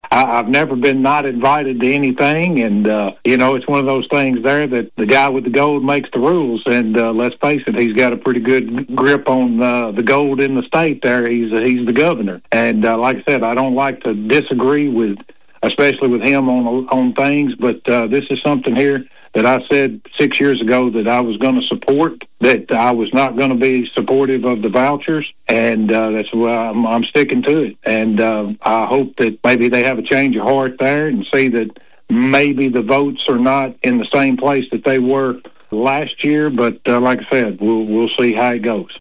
Representative Darby goes on to say that though he and the Governor have opposing views on this topic, there are other items they agree on.